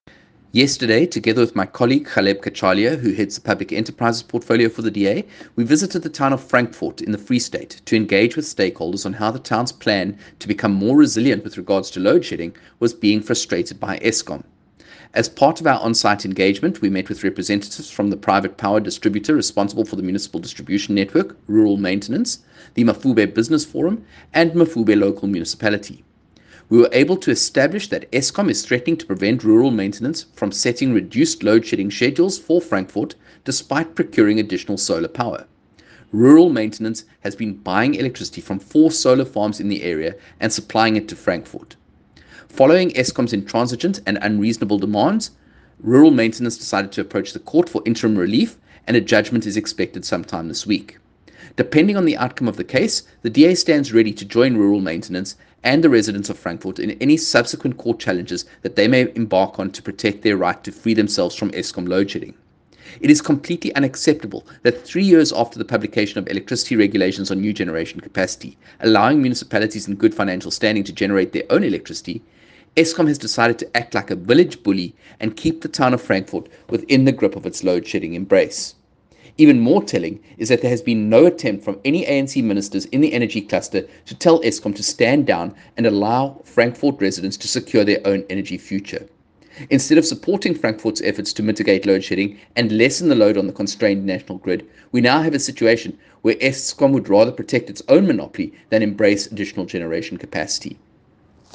Kevin-English-soundbite.mp3